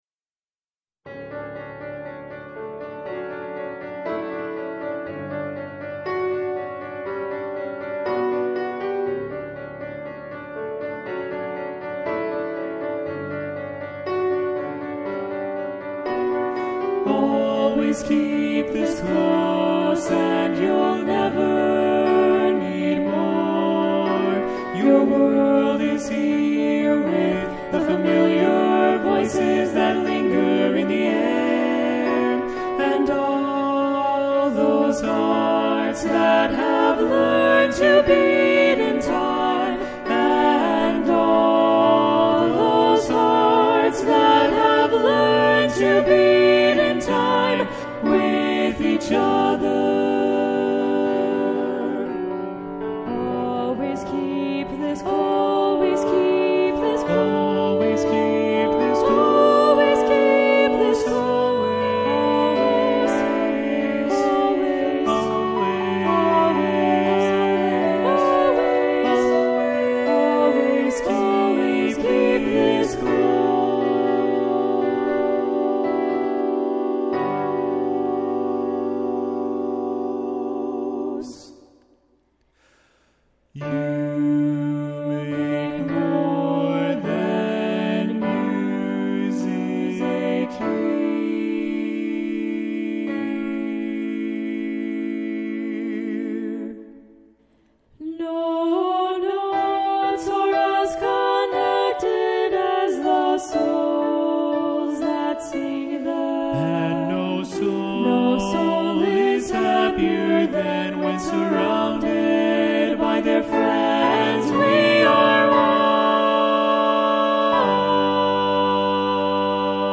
For SAB, SATB, TTBB, or SSAA with piano and string quartet